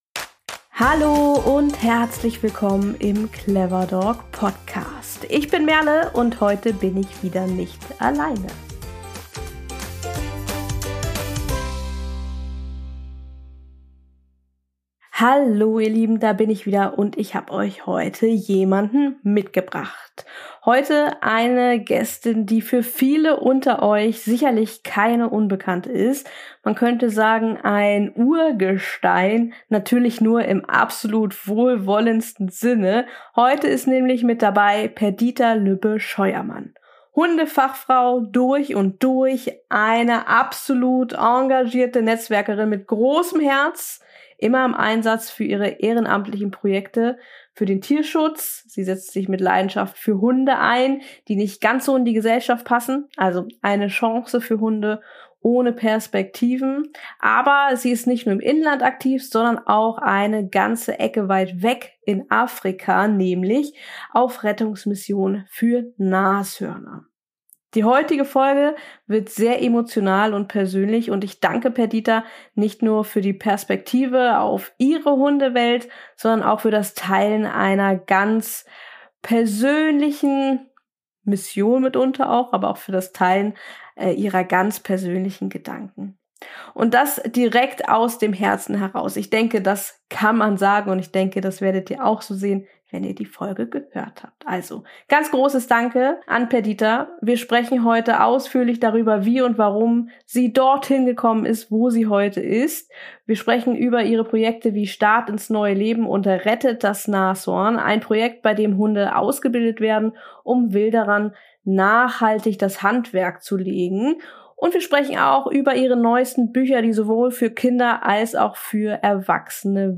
Dabei könnt ihr einem sehr emotionalen und persönlichen Gespräch lauschen.